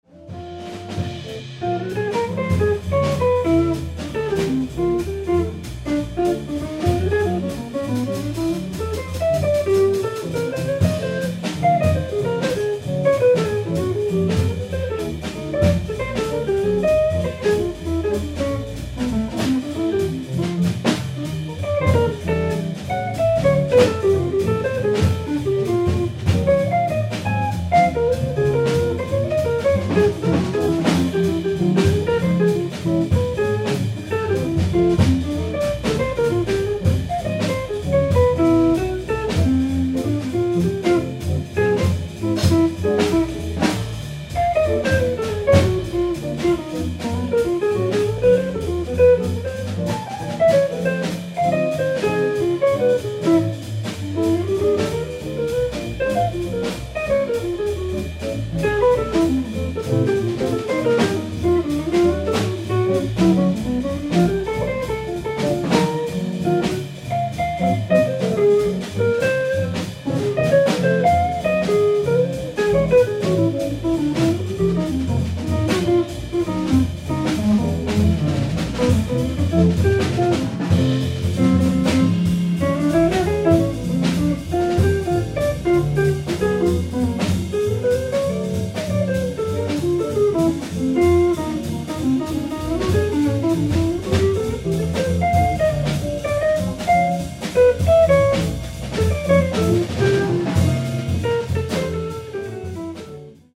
ライブ・アット・東京 06/11+12/2025
※試聴用に実際より音質を落としています。